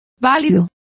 Complete with pronunciation of the translation of lawful.